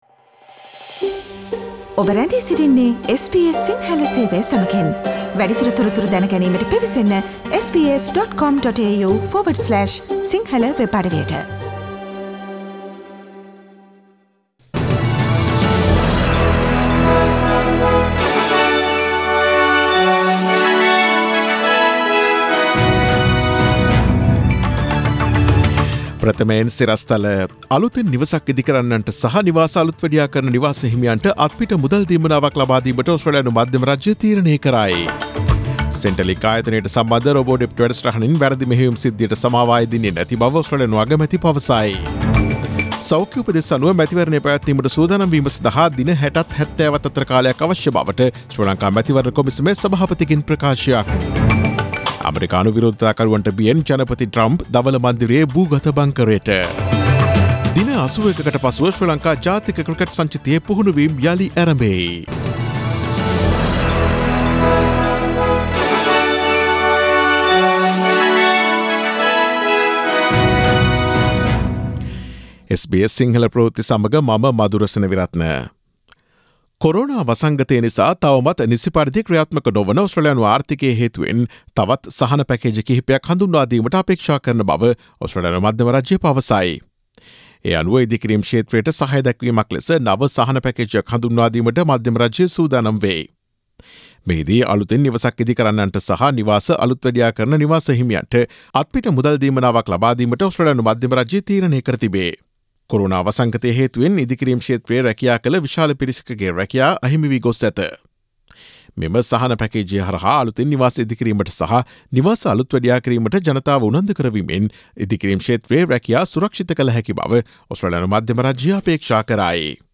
Daily News bulletin of SBS Sinhala Service: Tuesday 02 June 2020
Today’s news bulletin of SBS Sinhala Radio – Tuesday 02 June 2020 Listen to SBS Sinhala Radio on Monday, Tuesday, Thursday and Friday between 11 am to 12 noon